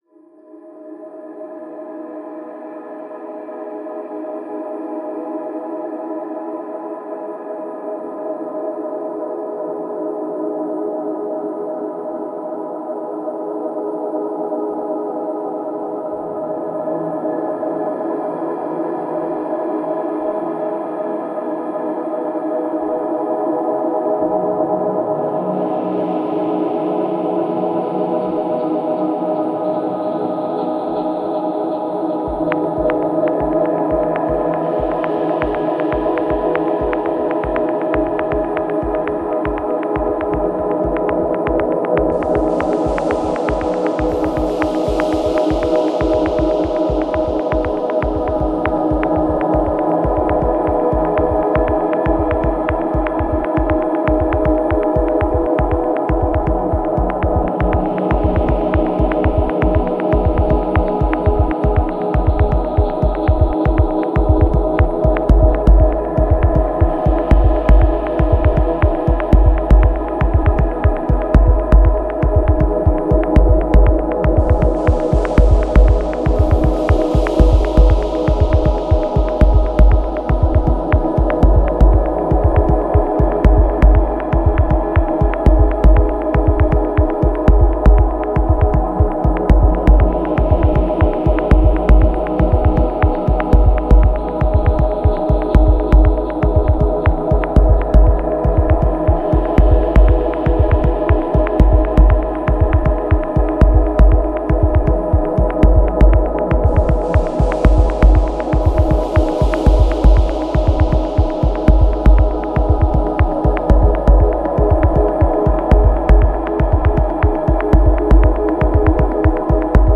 Genre: Techno/Ambient.